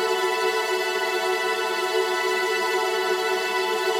GS_TremString-A6+9.wav